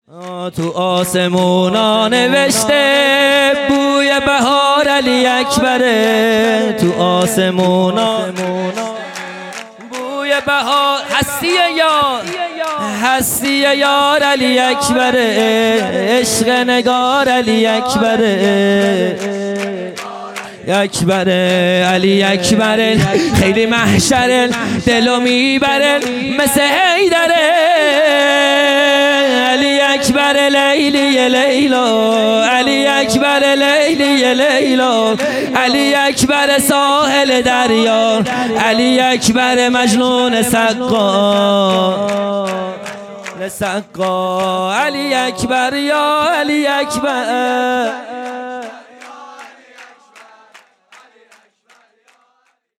خیمه گاه - هیئت بچه های فاطمه (س) - سرود | تو آسمونا نوشته بوی بهار علی اکبره
جلسۀ هفتگی (به مناسبت ولادت حضرت علی اکبر(ع))